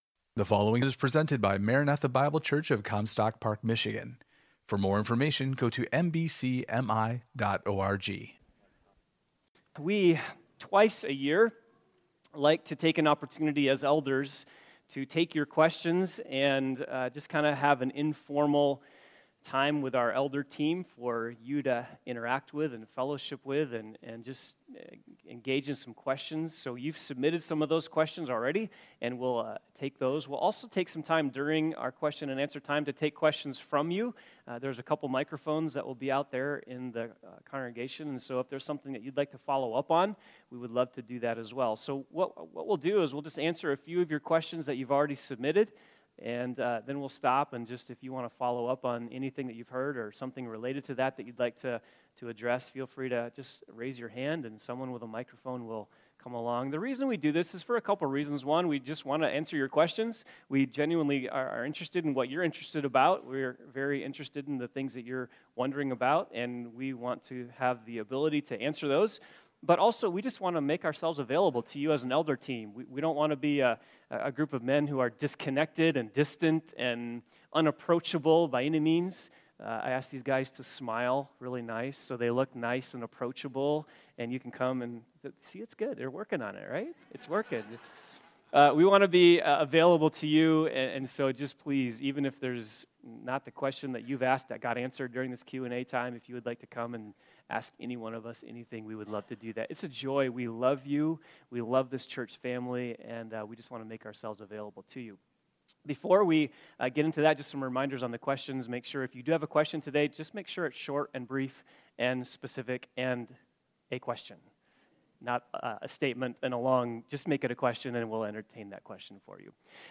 Elders’ Q&A